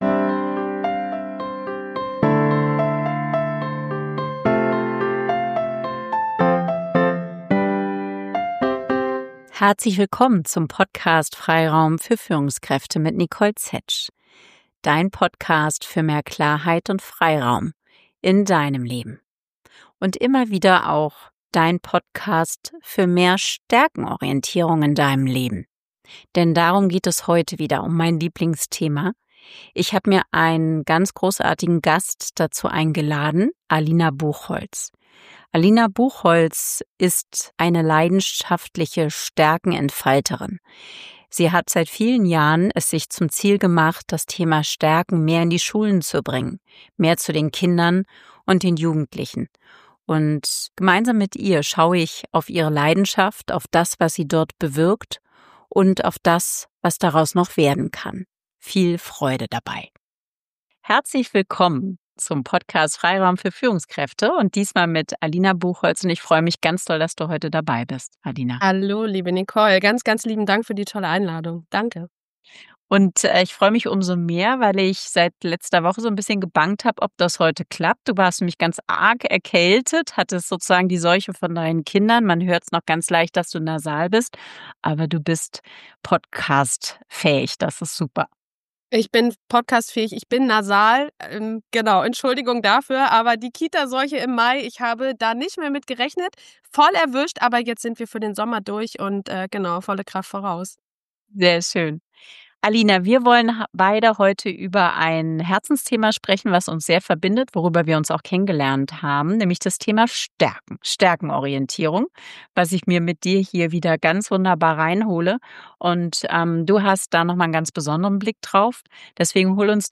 #71 Starke, resiliente Kinder & Jugendliche – ein Gespräch